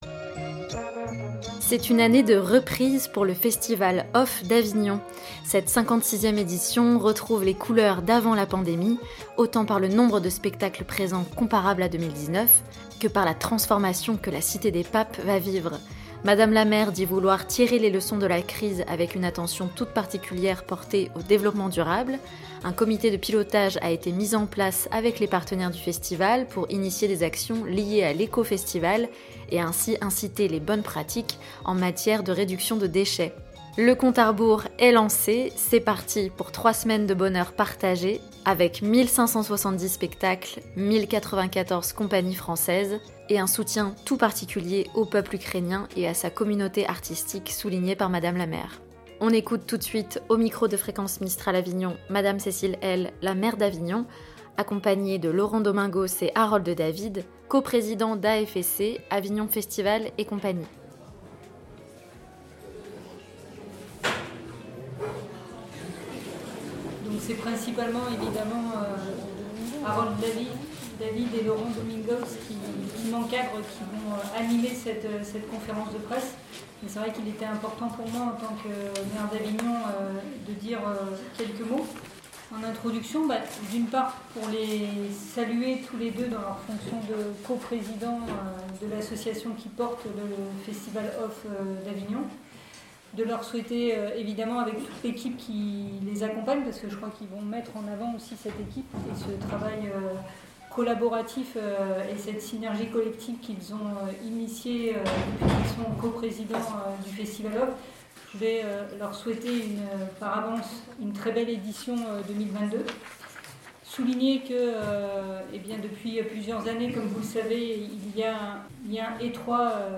On écoute tout de suite au micro de Fréquence Mistral Avignon Mme la Maire d'Avignon Cécile Helle